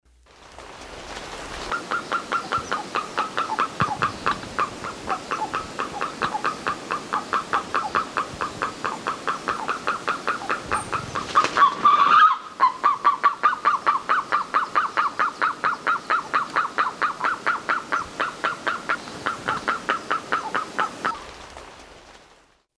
Andean Guan15.mp3